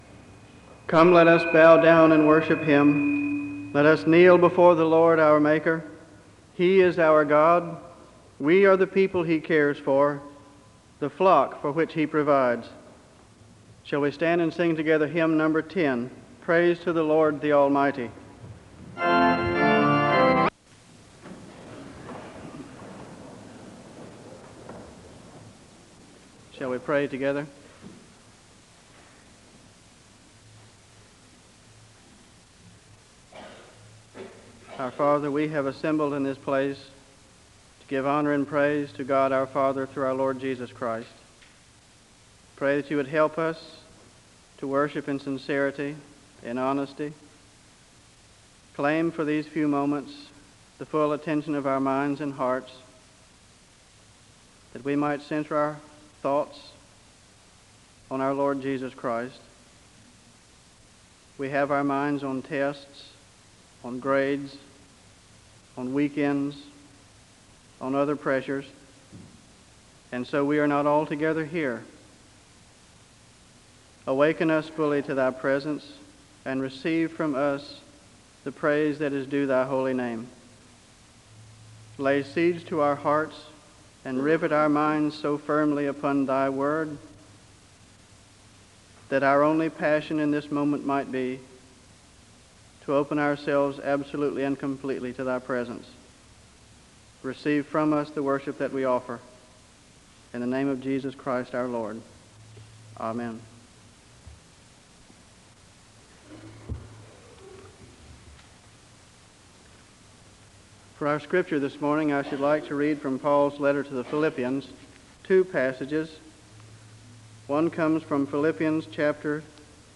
The service begins with a word of prayer (00:00-01:45).
The choir sings a song of worship (03:50-07:20).